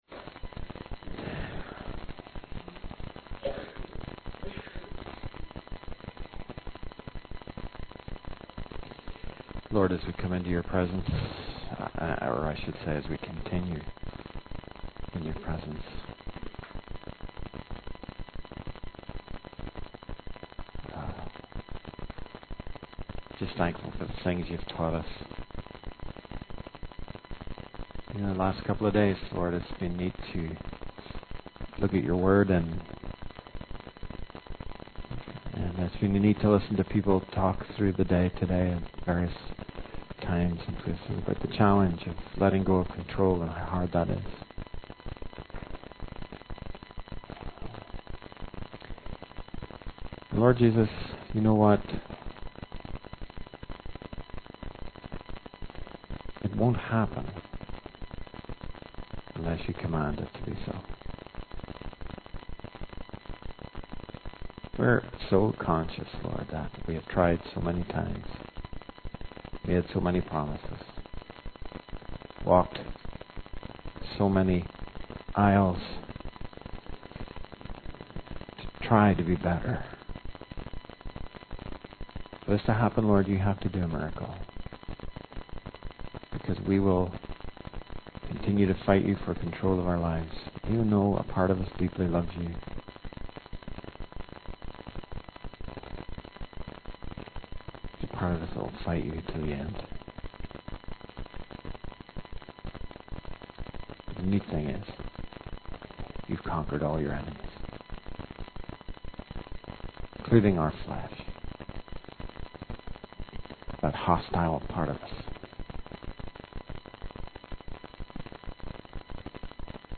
In this sermon, the preacher emphasizes the power of the Gospel and how it can transform lives. He encourages his listeners to step off the path of selfishness and stop playing games with their faith.